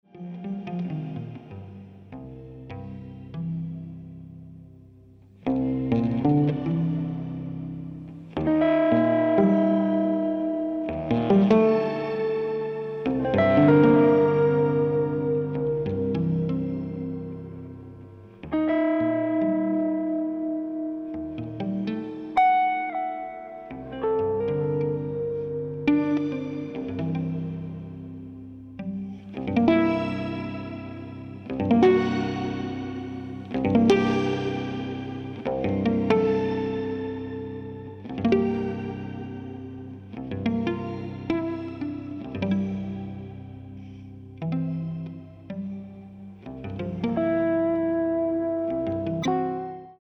爵士大提琴跨界專輯